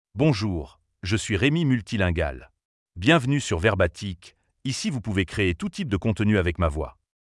Remy MultilingualMale French AI voice
Remy Multilingual is a male AI voice for French (France).
Voice sample
Listen to Remy Multilingual's male French voice.
Remy Multilingual delivers clear pronunciation with authentic France French intonation, making your content sound professionally produced.